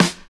47 SNARE.wav